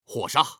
male